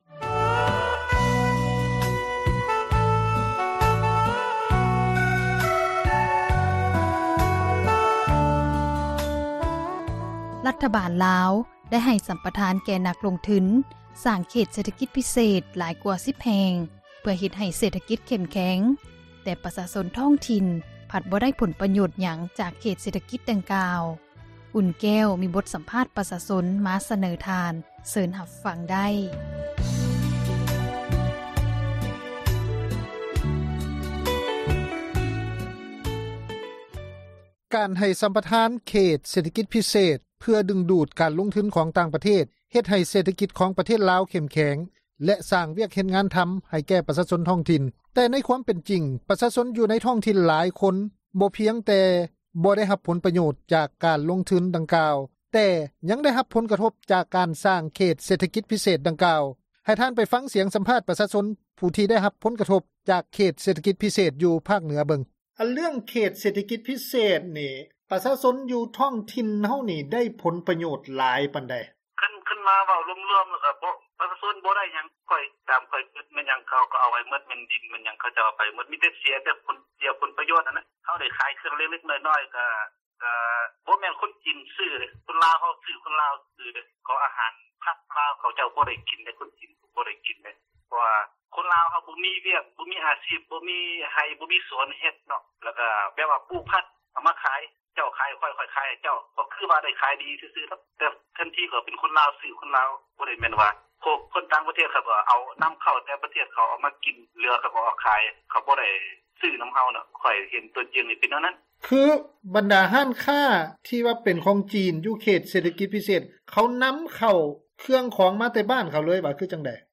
ໃຫ້ທ່ານ ໄປຟັງສຽງສຳພາດ ປະຊາຊົນ ຜູ້ທີ່ໄດ້ຮັບຜົນກະທົບຢູ່ພາກເໜືອເບິ່ງ.